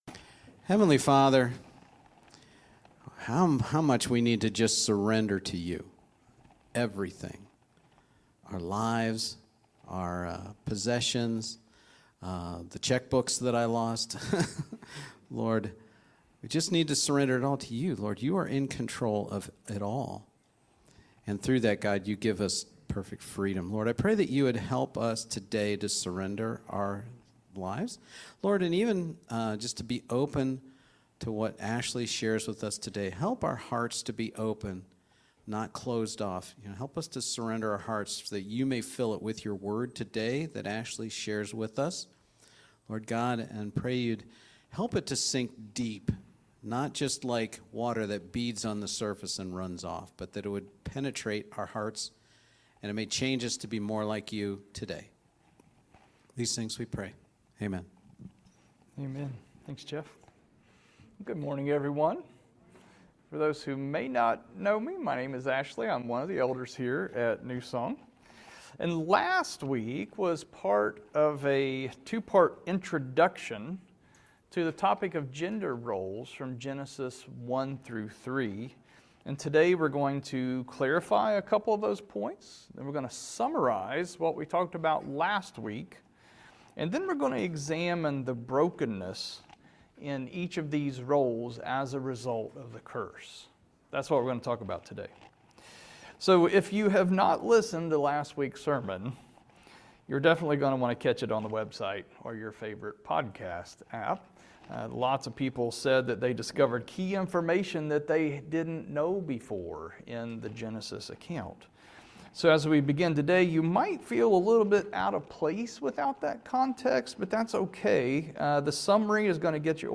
Sermons | New Song Community Church